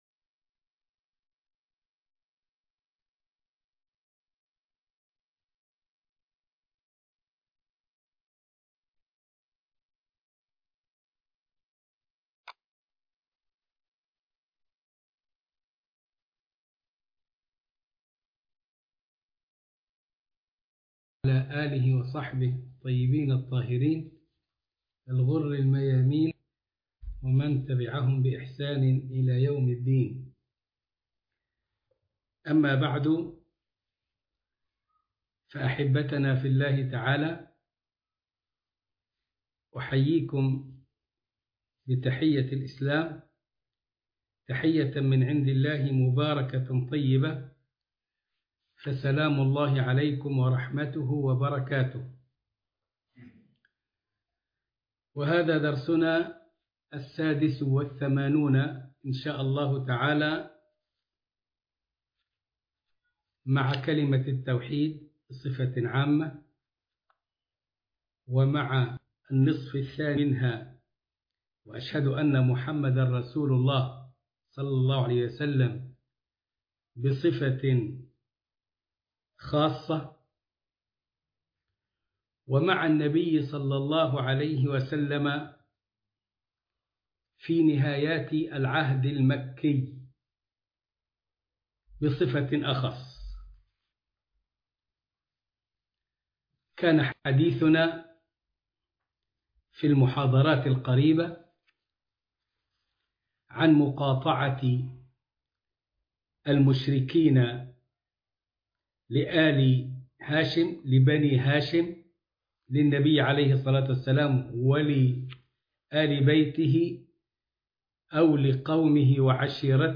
المحاضرة السادسة والثمانون